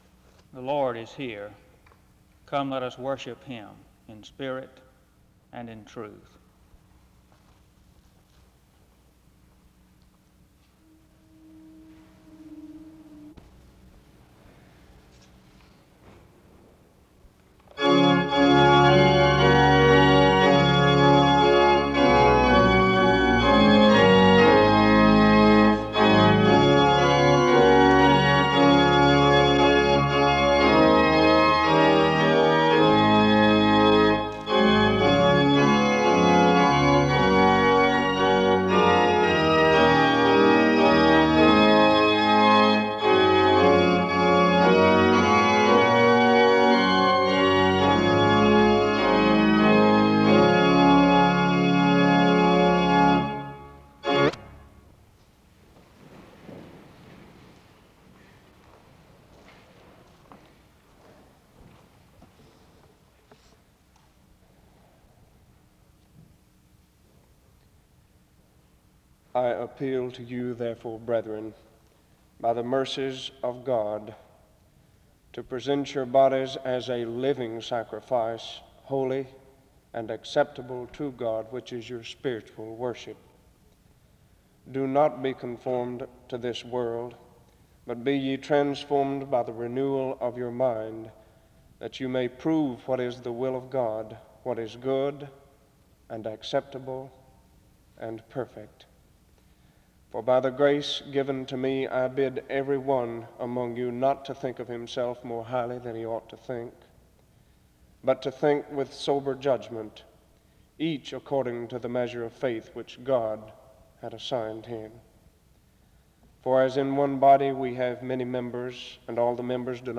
Download .mp3 Description The service begins with music from 0:17-0:55. A scripture reading from Romans 12 takes place from 1:09-4:09. A prayer is offered from 4:18-5:30.
Music plays from 10:46-15:24.
Romans 12 Language English Identifier SEBTS_Chapel_Student_Coordinating_Council_1964-04-16 Date created 1964-04-16 Location Wake Forest (N.C.)